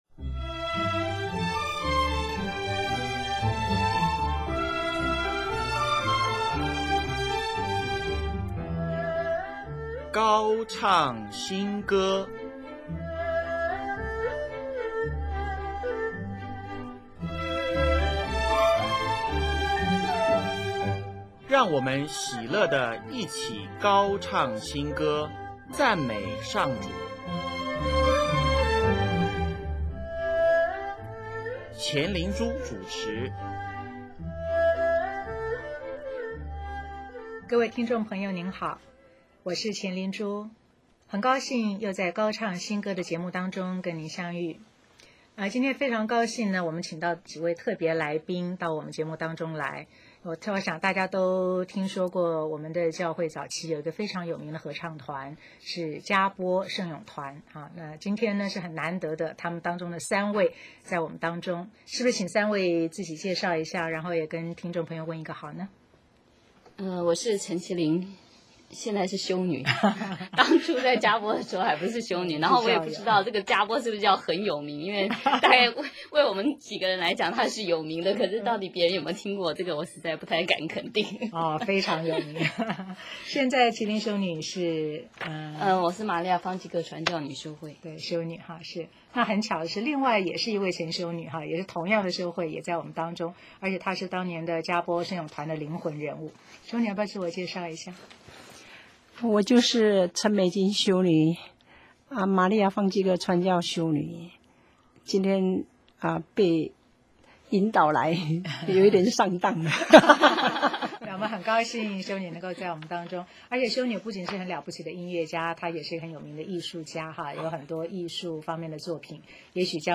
【高唱新歌】5|专访“佳播圣咏团”(一)：歌声飘到修院外